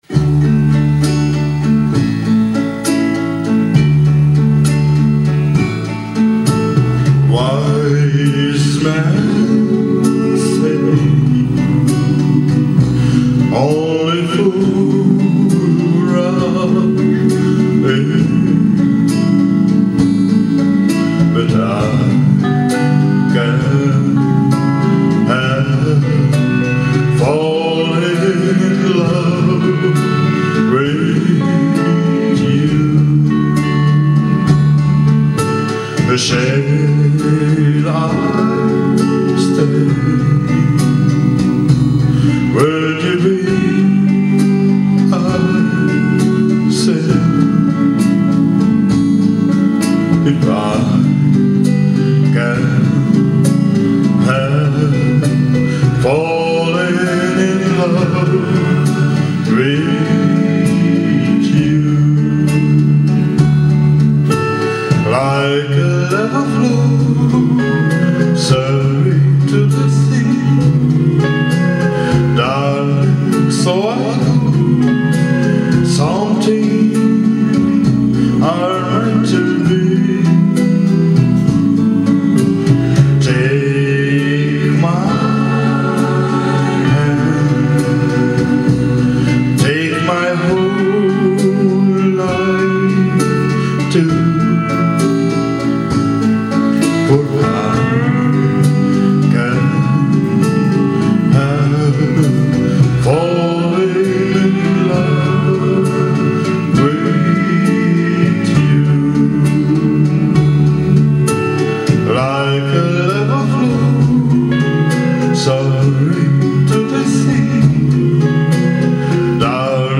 készült Budapesten az Andretti cukrászdában